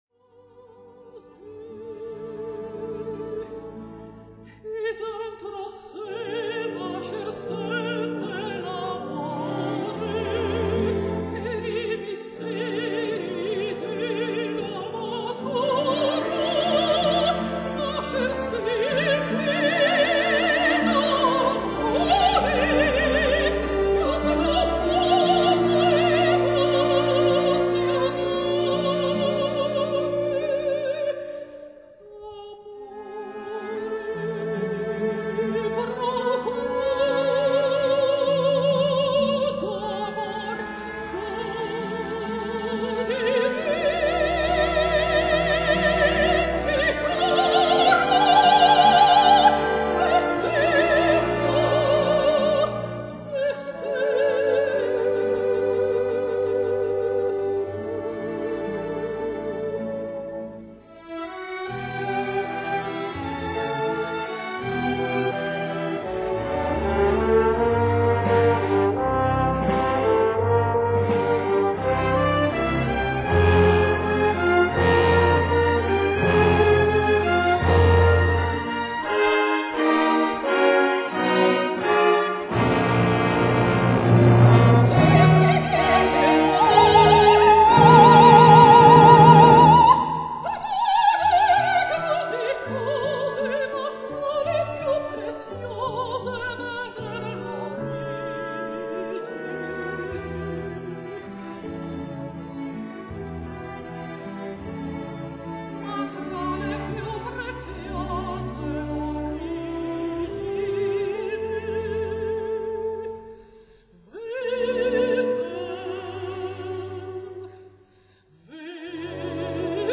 Cantata per Soprano, Voce recitante,
Teatro Comunale di Chiaravalle (AN)
Recitativo e Aria (soprano e orchestra)